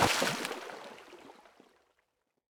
small-splash-1.ogg